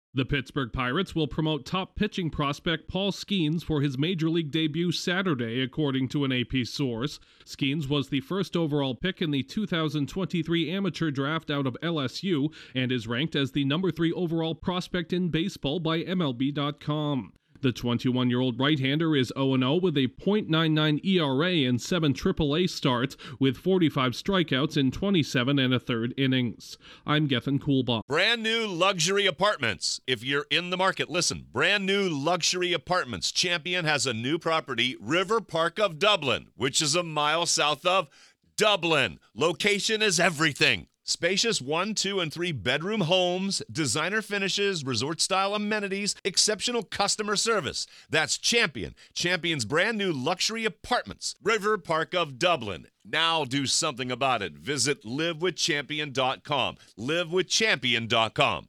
One of baseball’s top prospects is set to make his major league debut this weekend. Correspondent